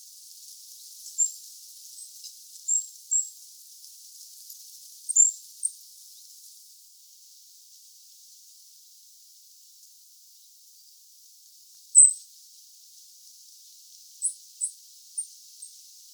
Regulus regulus - Goldcrest - Regolo
- IDENTIFICATION AND BEHAVIOUR: In an artificial wood of silver fir there is a Goldcrest feeding in a tree (well in sight).
E 11°28' - ALTITUDE: +950 m. - VOCALIZATION TYPE: contact calls.
- COMMENT: The spectrograms show at least 3 different call types.